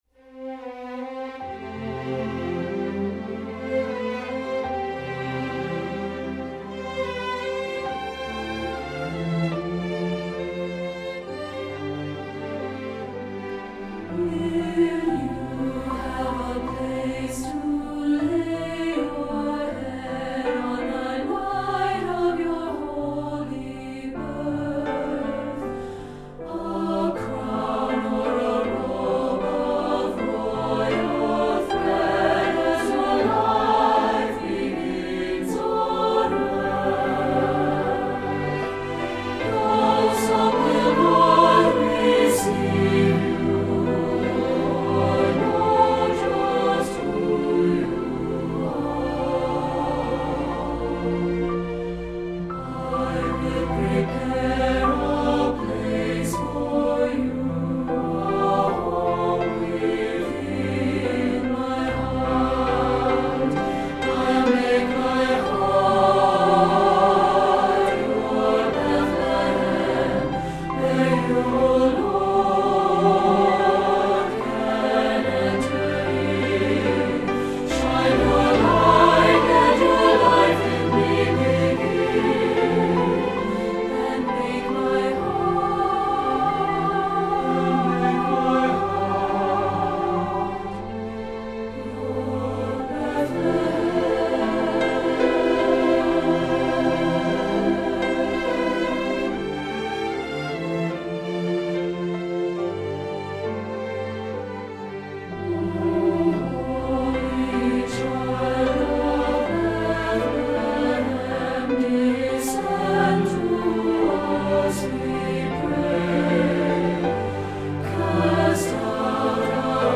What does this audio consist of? Voicing: SAB